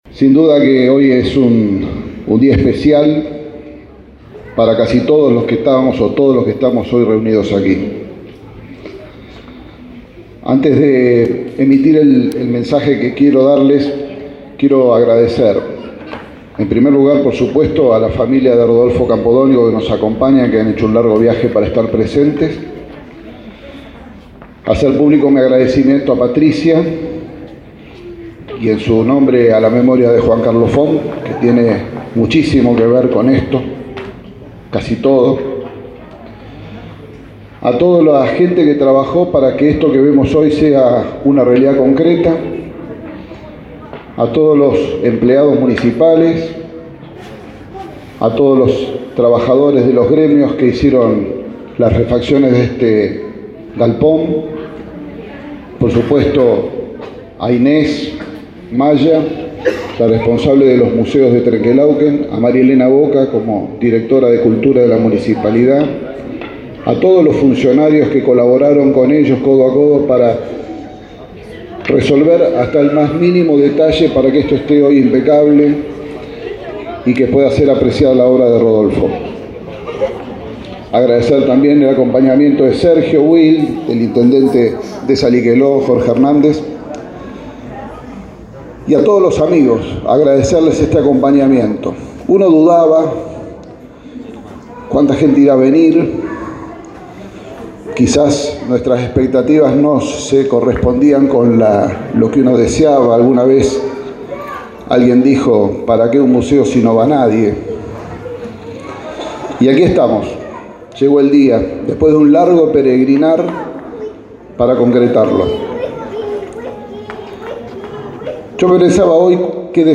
El Intendente Municipal Miguel Fernández habló en la inauguración del Museo de los Murales donde están las 28 obras que realizo en pintor Rodolfo Campodónico.
Miguel-Fern--ndez-museo-de-los-murales.mp3